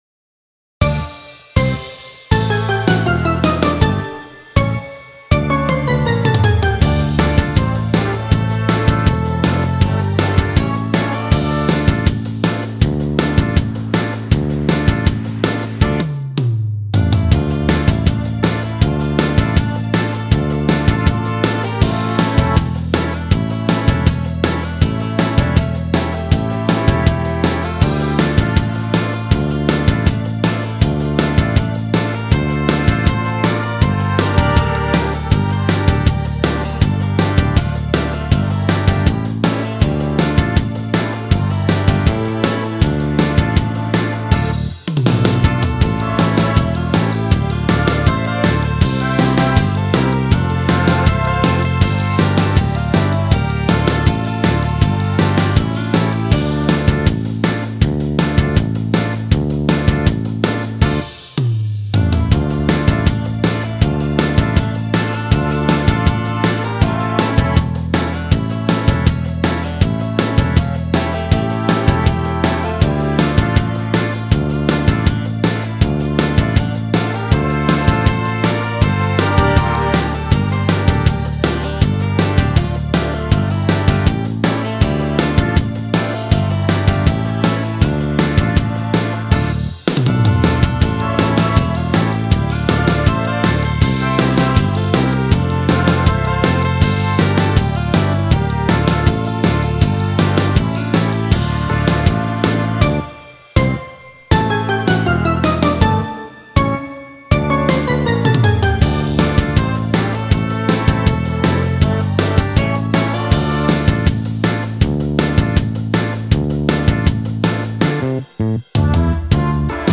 Мелодия без слов (минус)